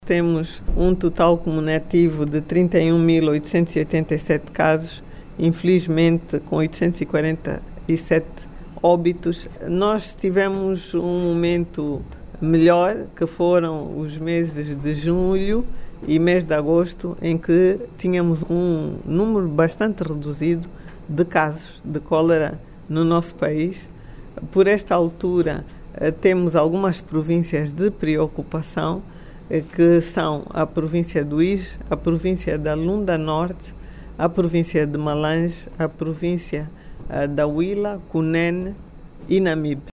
A  Ministra da Saúde, Sílvia Lutukuta  fez saber que  as províncias do Uíge, Lunda-Norte e  Malange estão entre as  mais  afectadas.
SILVIA-LUTUKUTA-13HRS.mp3